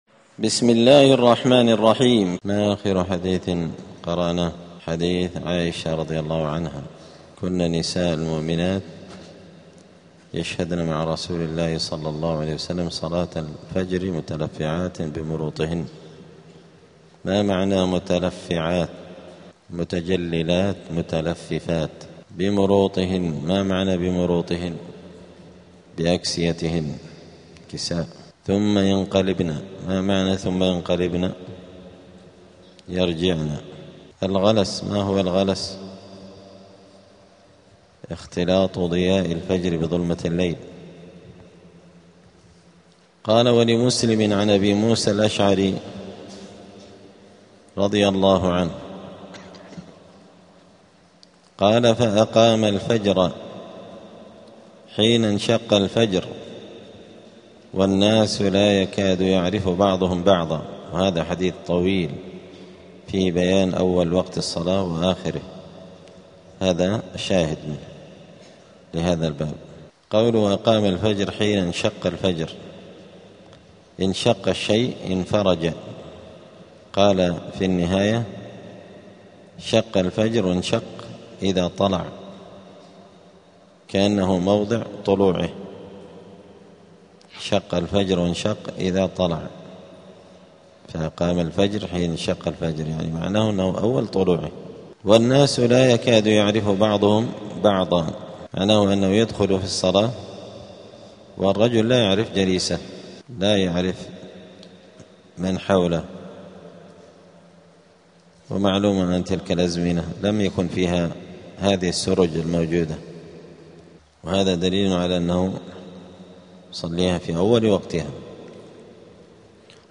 دار الحديث السلفية بمسجد الفرقان قشن المهرة اليمن
الدروس الأسبوعية